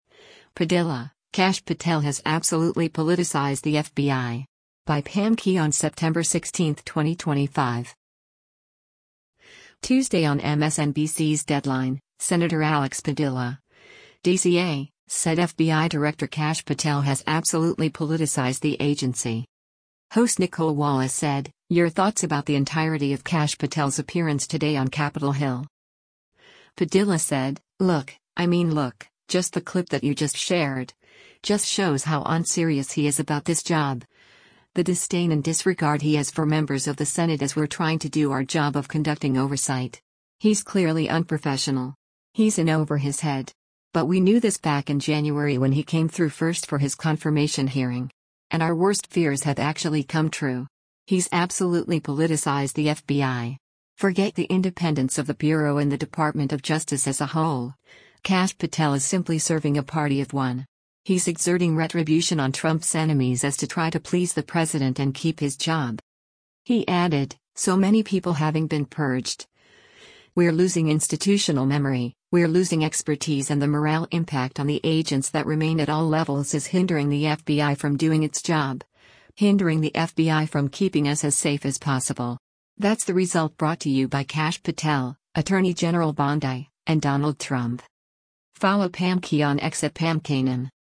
Tuesday on MSNBC’s “Deadline,” Sen. Alex Padilla (D-CA) said FBI Director Kash Patel has “absolutely politicized” the agency.
Host Nicolle Wallace said, “Your thoughts about the entirety of Kash Patel’s appearance today on Capitol Hill.”